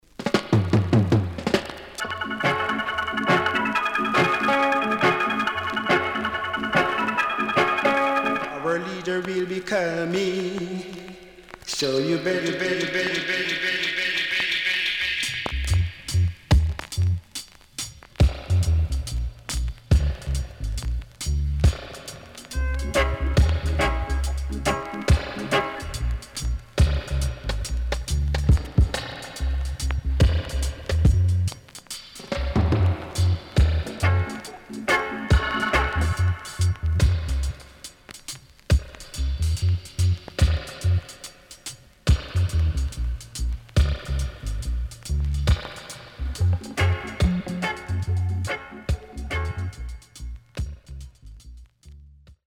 Very Rare.Great Roots 渋Vocal & Dubwise
SIDE A:所々ノイズ入ります。